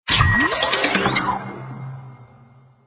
fx thinking